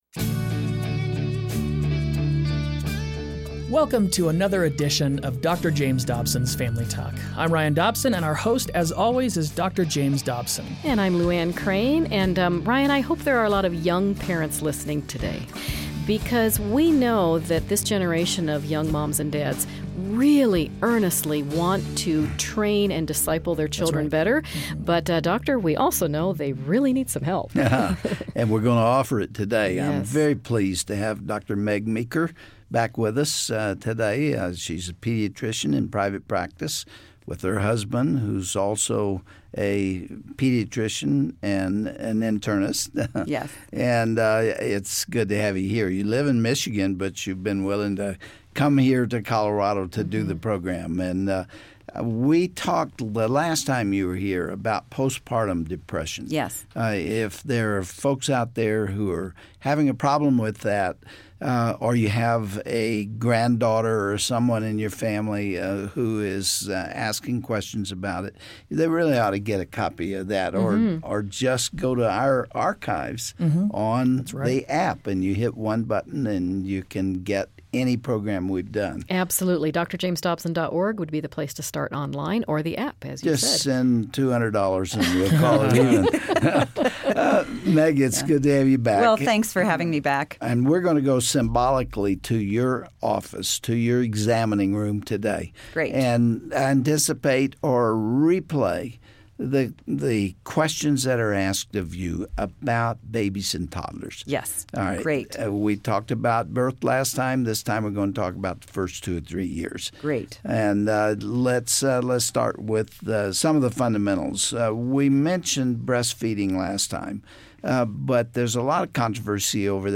But you probably DO have real questions on things like breastfeeding or sleep training , and we the have answers to those questions! Hear trusted, time-proven advice, as Dr. James Dobson and pediatrician Dr. Meg Meeker tackle topics from parenting during the early years!